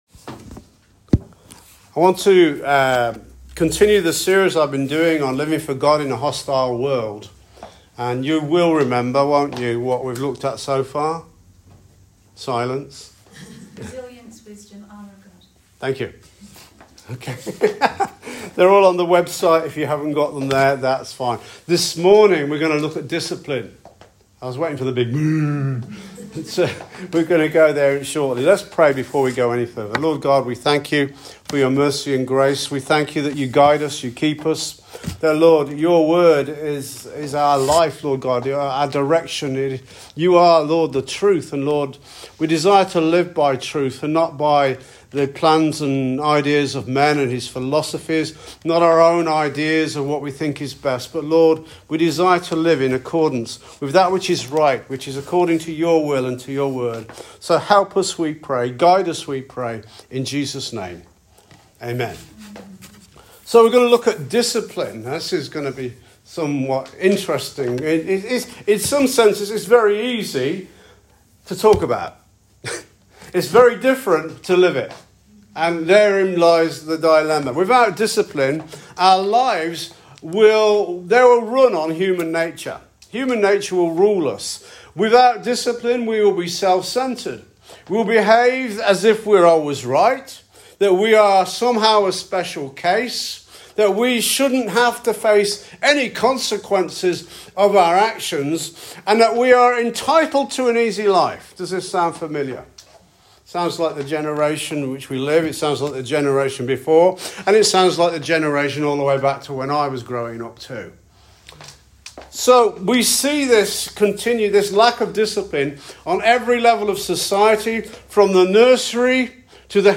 SERMON “DISCIPLINE